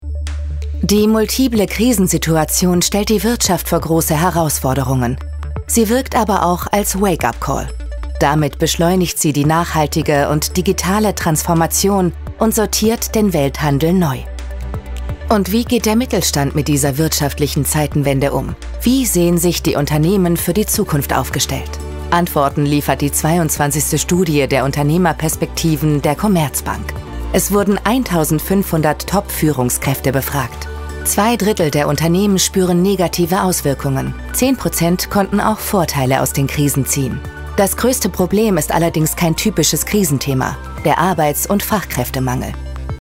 Werbung - Commerzbank